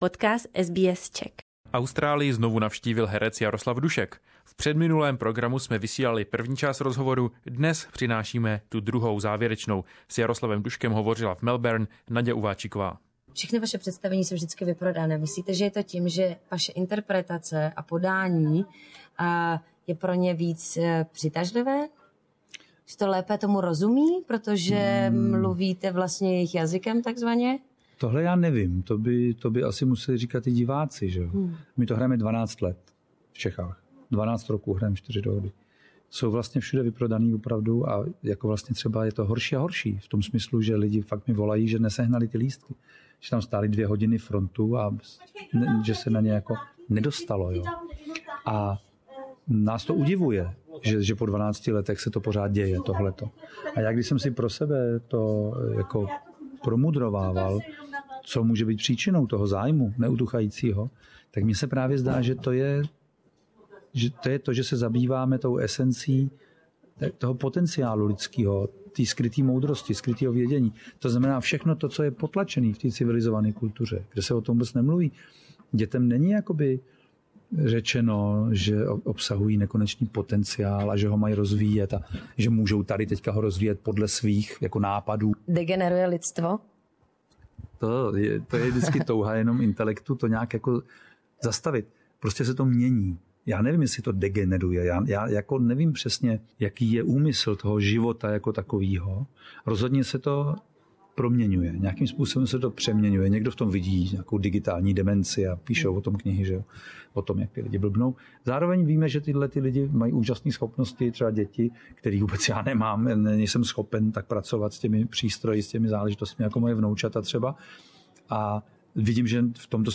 Jaroslav Dusek toured in Australia for the third time. Two weeks ago we featured the first part of his interview, now, we have the second one.